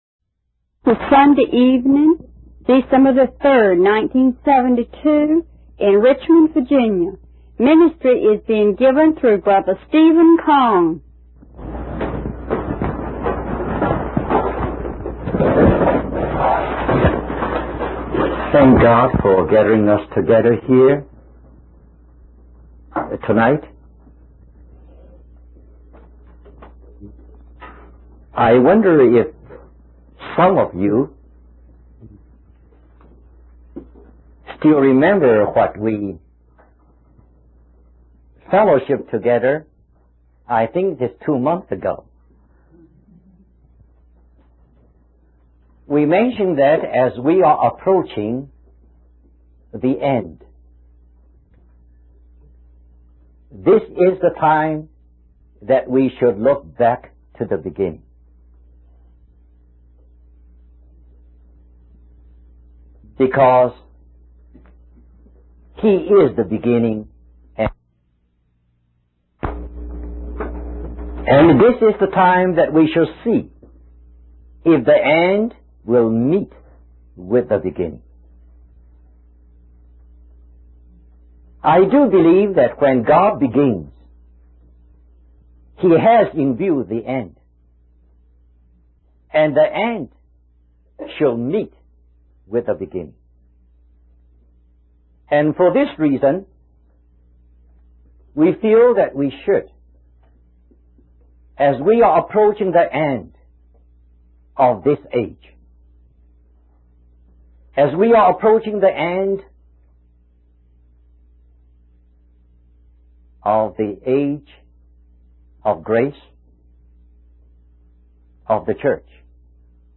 In this sermon, the preacher reflects on the story of a young ruler who approached Jesus with a sincere heart for spiritual matters.